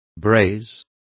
Complete with pronunciation of the translation of brazed.